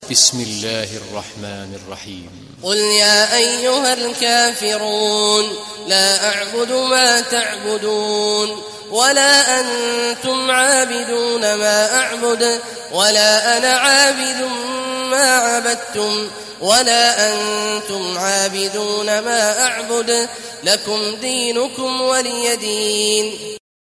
Sourate Al Kafirun mp3 Télécharger Abdullah Awad Al Juhani (Riwayat Hafs)
Sourate Al Kafirun Télécharger mp3 Abdullah Awad Al Juhani Riwayat Hafs an Assim, Téléchargez le Coran et écoutez les liens directs complets mp3